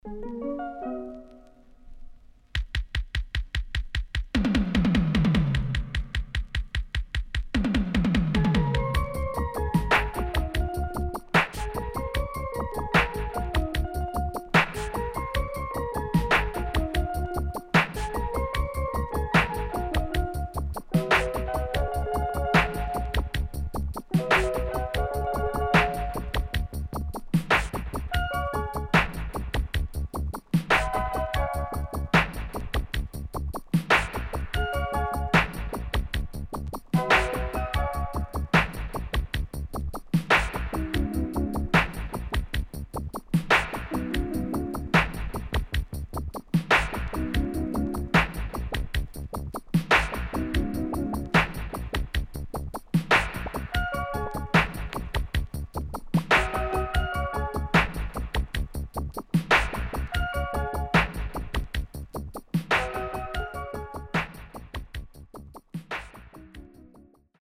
SIDE A:少しノイズ入りますが良好です。